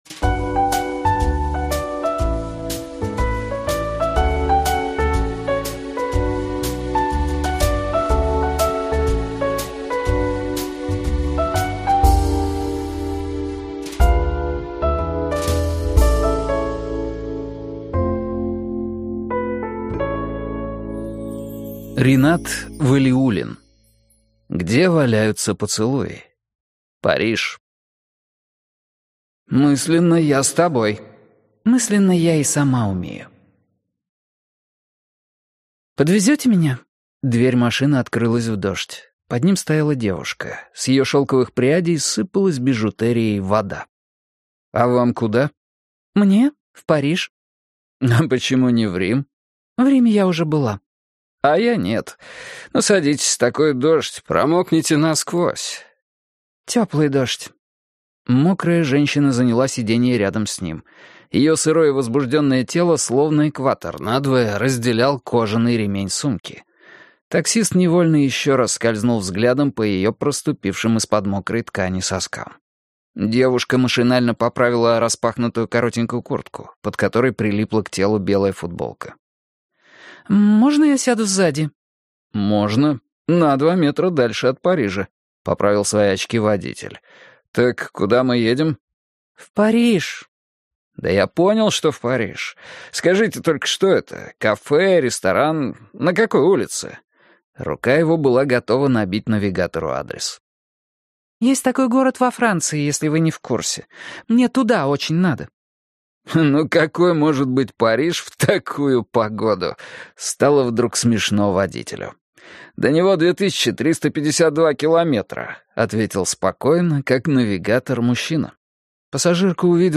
Аудиокнига Где валяются поцелуи. Париж - купить, скачать и слушать онлайн | КнигоПоиск